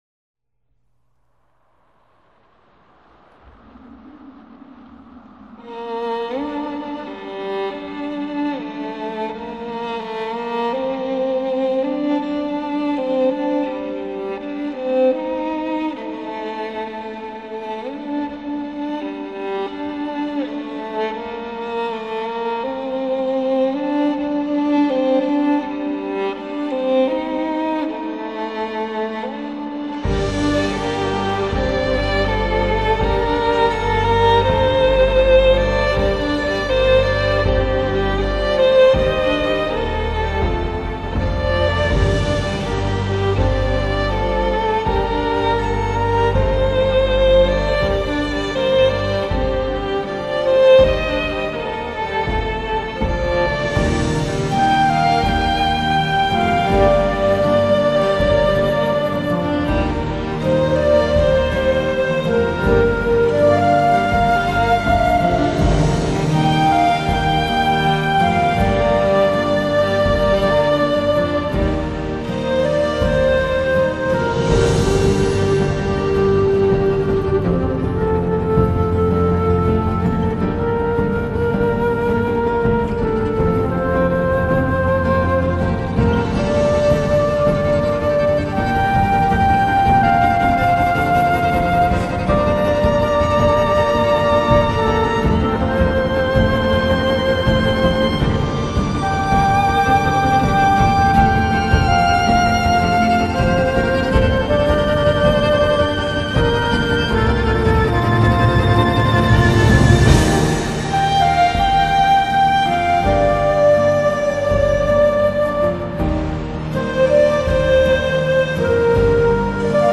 钢琴的演奏低缓而涌动，清脆而强硬。小提琴高亢而嘹亮，牵引着旋律的丝线逐步高升。